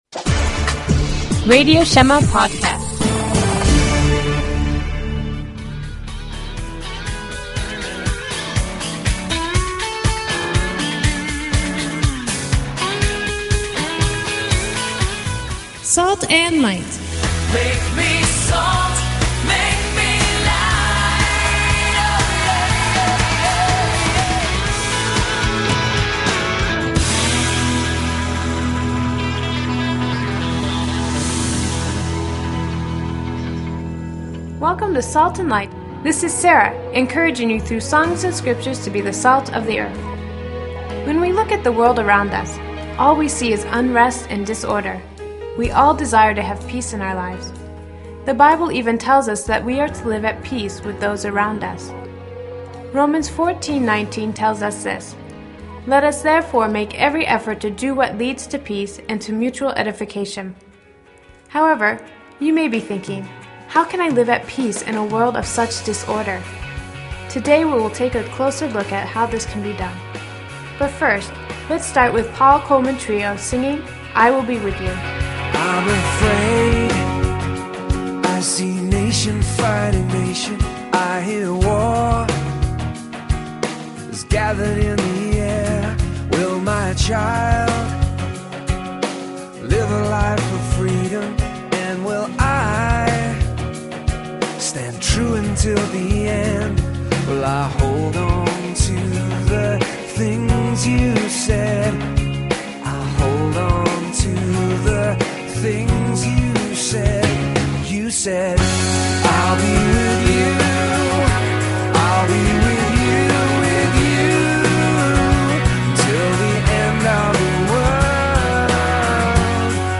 Basit İngilizcede Kutsal Kitap’tan okumalar ve ilahiler.